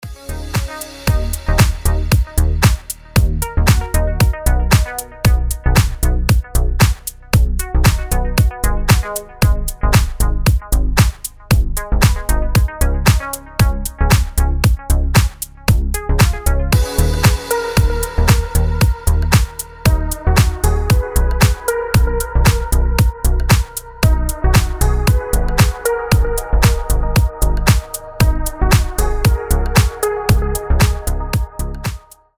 • Качество: 320, Stereo
ритмичные
deep house
без слов
nu disco
Indie Dance
Хороший ню диско